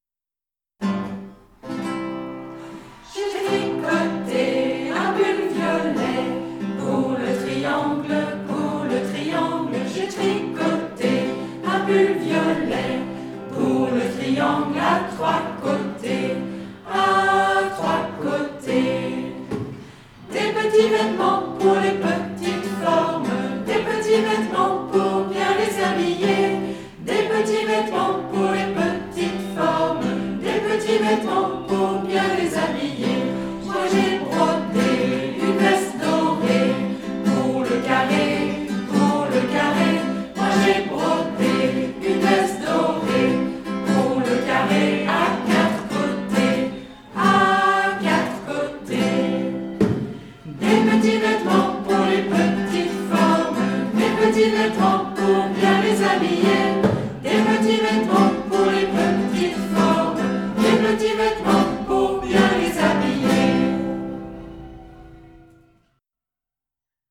Forme couplet-refrain